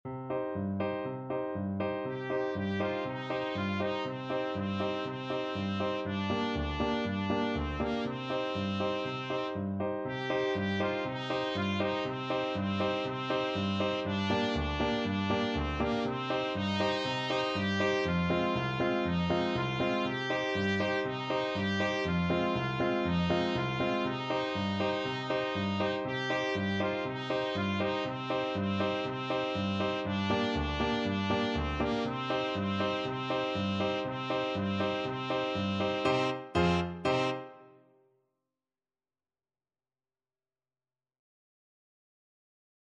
Allegro vivo (View more music marked Allegro)
4/4 (View more 4/4 Music)
World (View more World Trumpet Music)